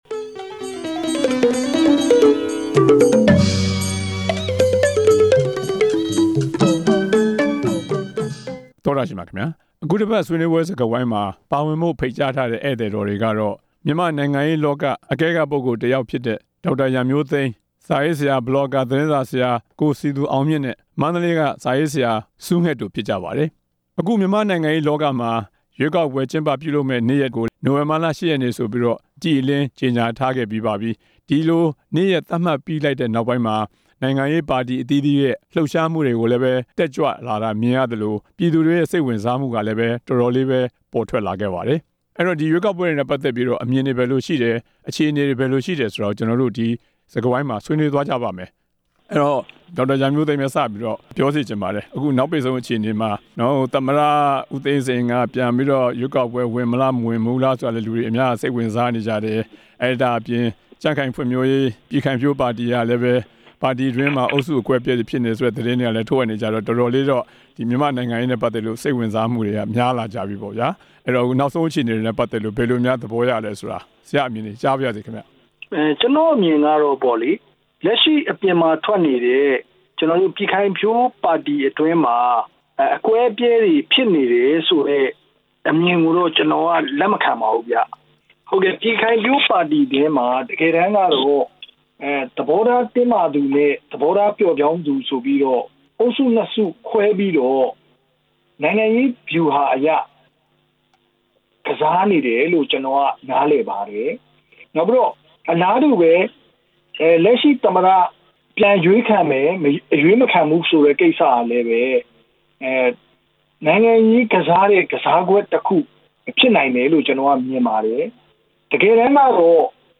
၂ဝ၁၅ ရွေးကောက်ပွဲ အခြေအနေ ဆွေးနွေးချက်